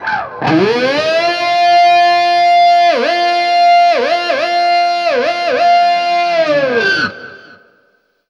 DIVEBOMB13-R.wav